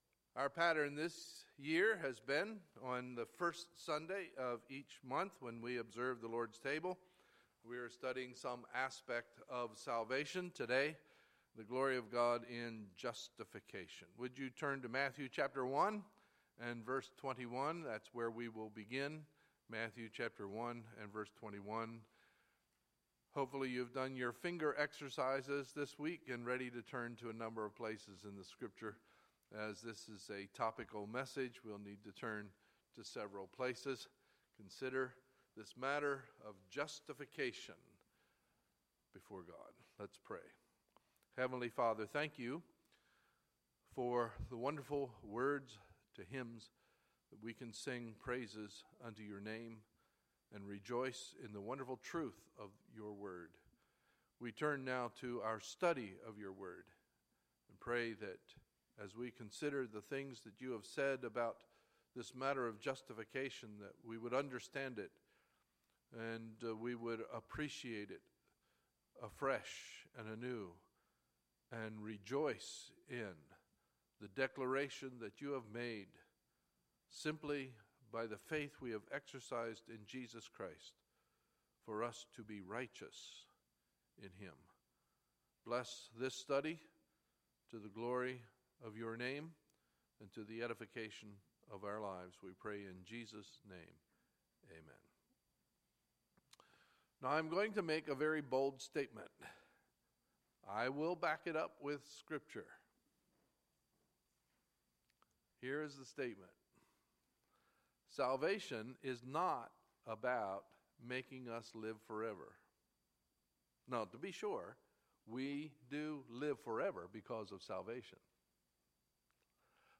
Sunday, September 4, 2016 – Sunday Morning Service